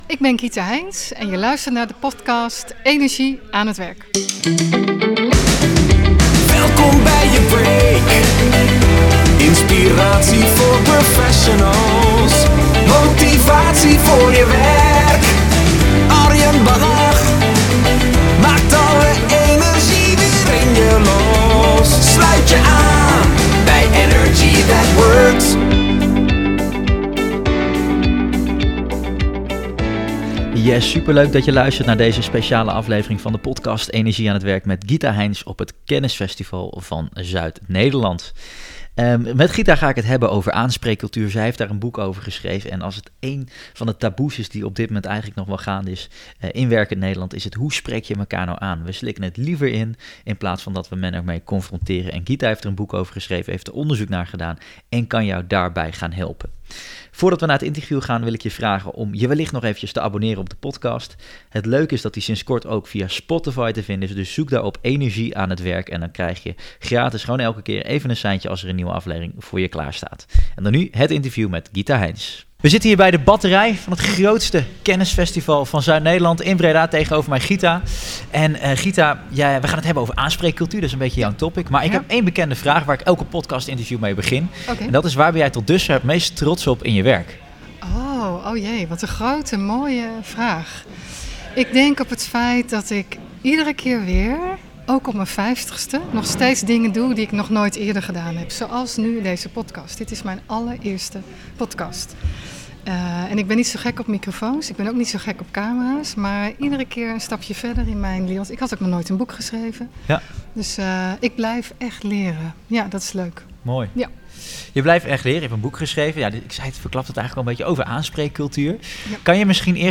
live op 3 FM